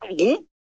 Звуки глотка
Звуки глотка: как персонажи мультфильмов издают этот звук